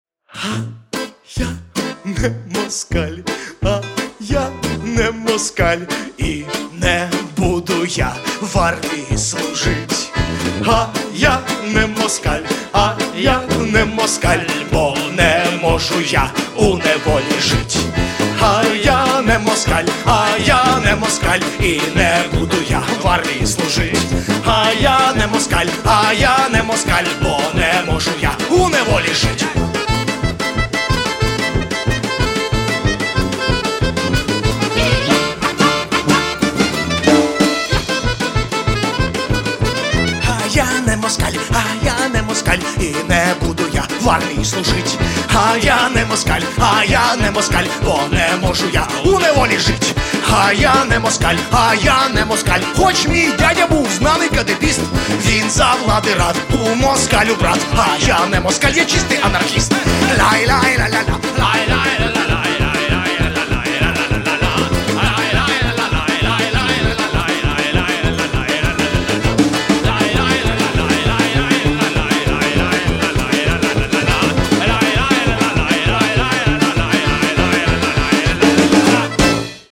• Качество: 256, Stereo
веселые
инструментальные
украинские